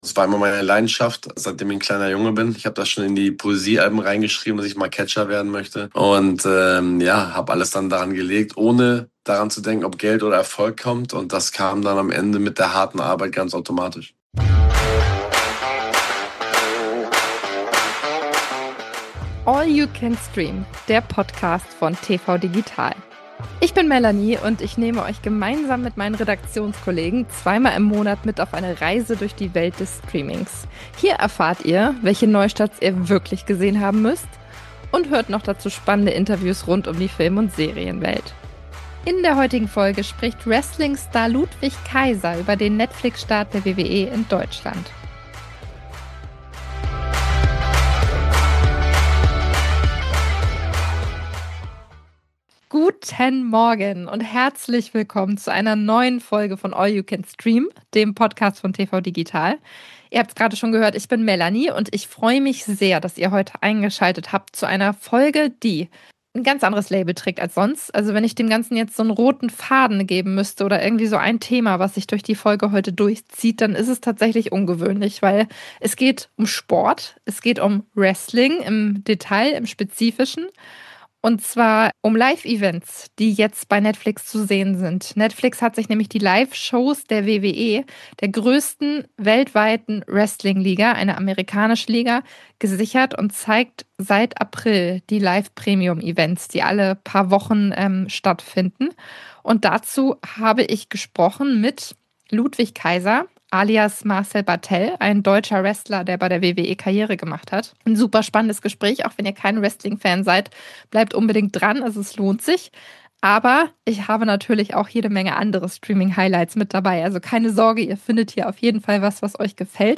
Die größten Streaming-Highlights von Mitte bis Ende April. UND: Exklusives Interview mit Wrestler Ludwig Kaiser über den Netflix-Start der WWE in Deutschland